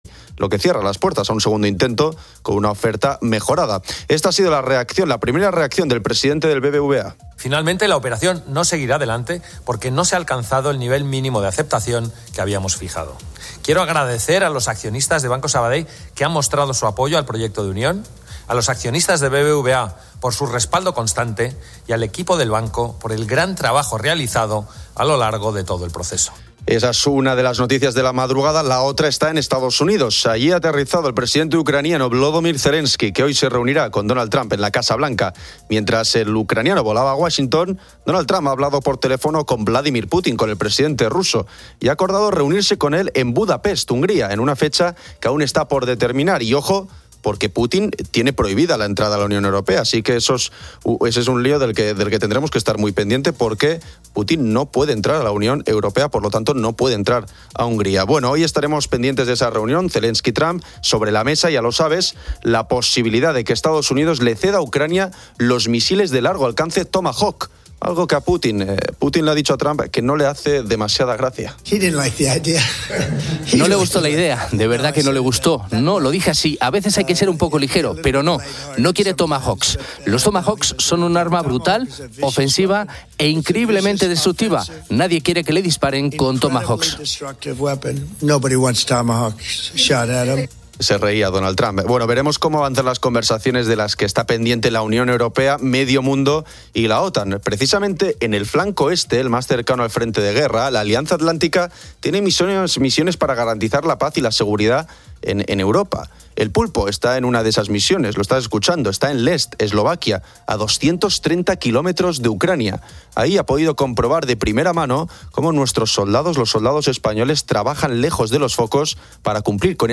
El programa de COPE Poniedo las calles se emite desde la base de la OTAN en Eslovaquia.
Los oyentes comparten sus experiencias con el sueño, desde pesadillas recurrentes y sueños lúcidos hasta precauciones para un descanso tranquilo.